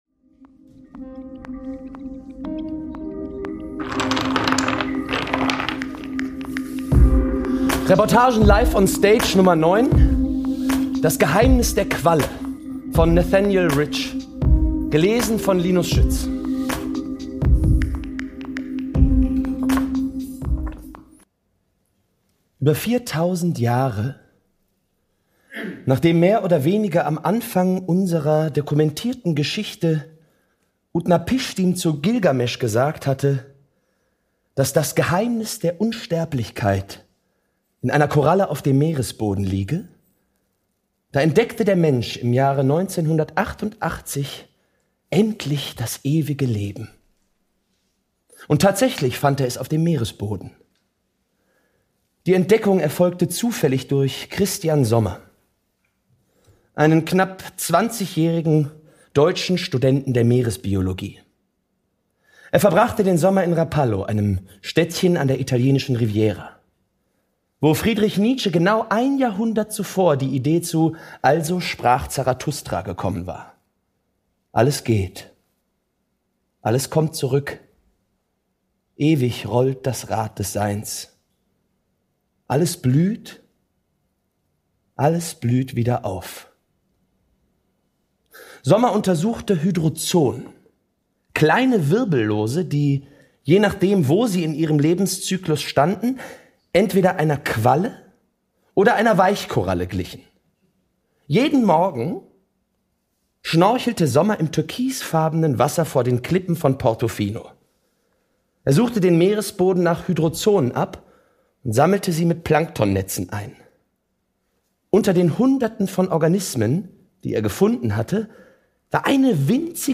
Das Geheimnis der Qualle ~ Reportagen Live on Stage Podcast